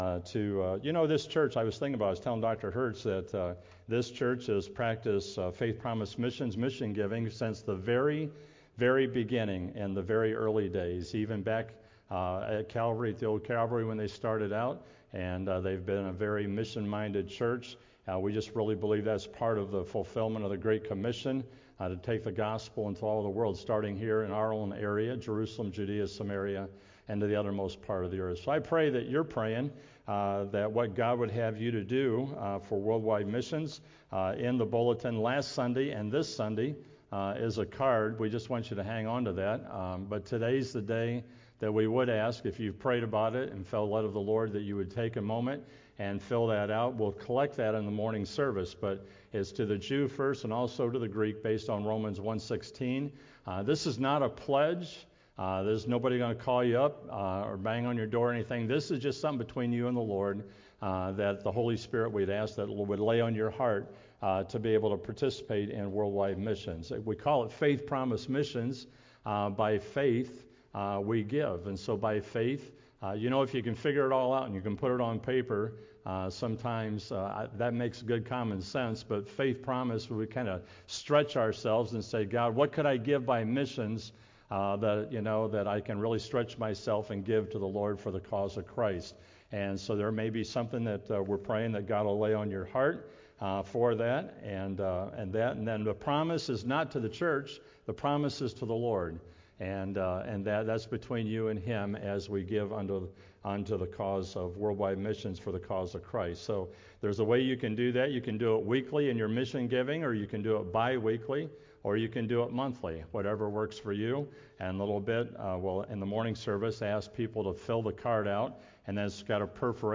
Sunday School